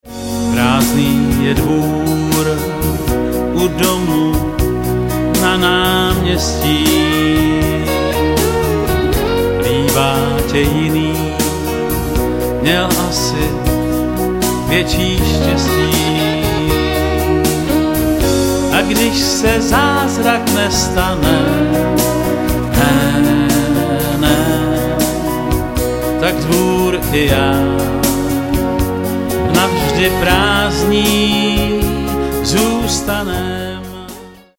pop-rock